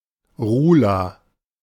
Ruhla (German pronunciation: [ˈʁuːla]
De-Ruhla.ogg.mp3